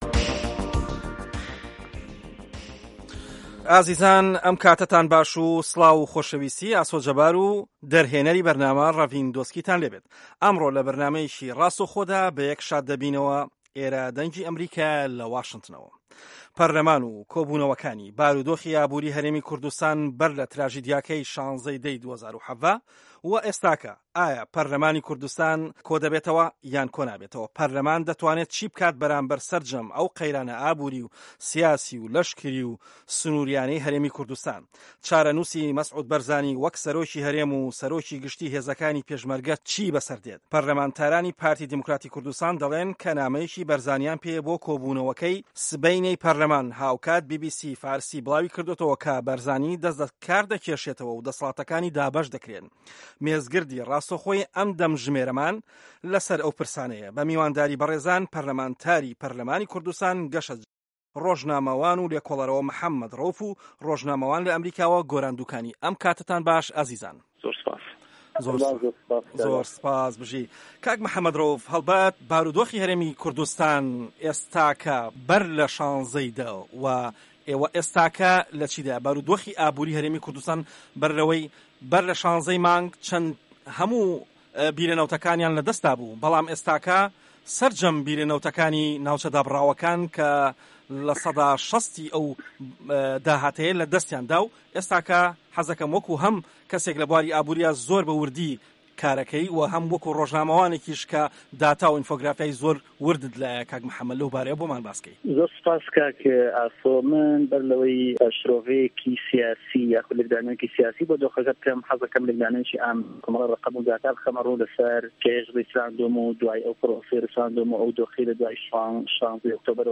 دەقی مێزگردەکە